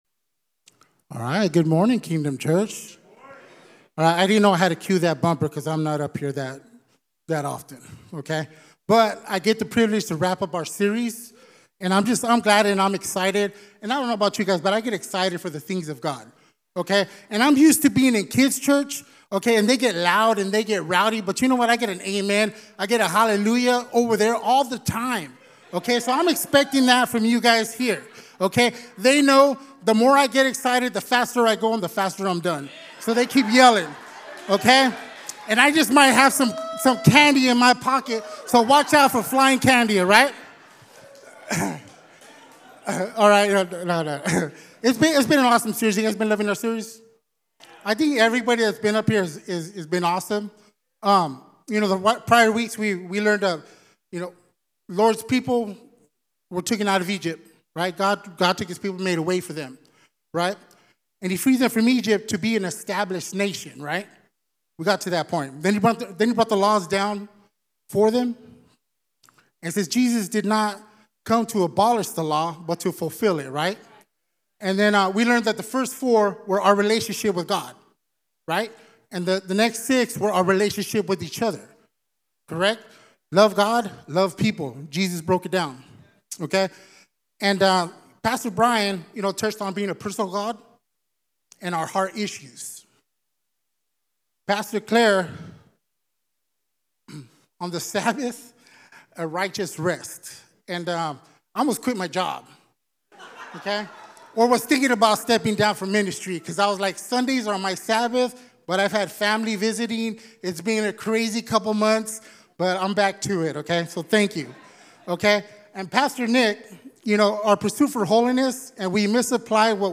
Sermons | Kingdom Church